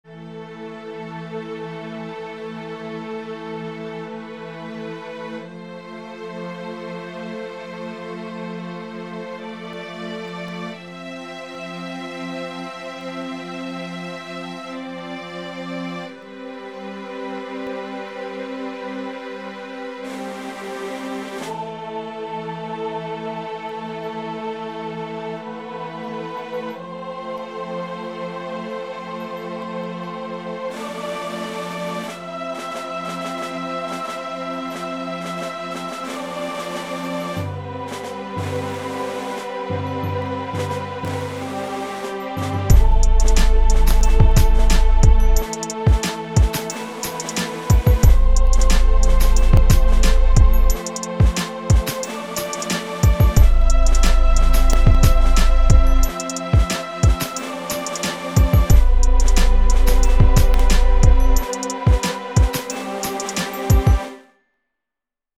Стиль: Ambient&Downtempo